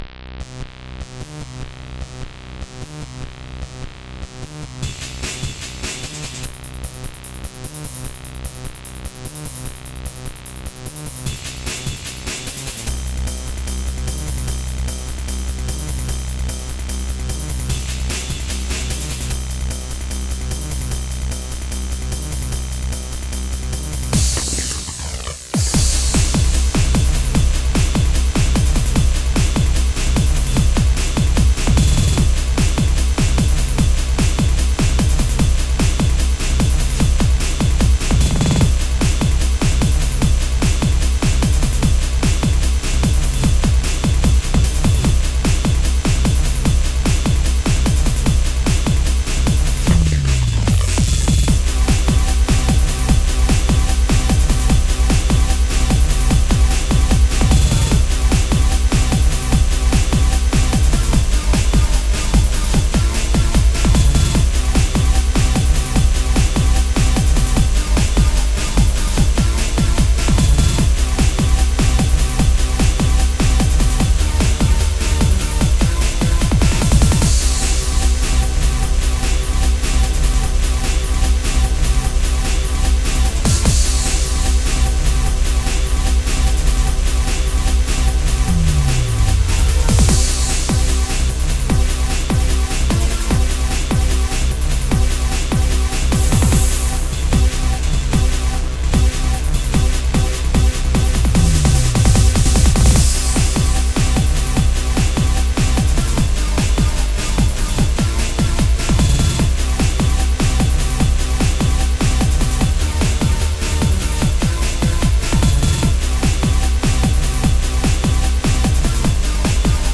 Stil: Breakbeat